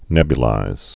(nĕbyə-līz)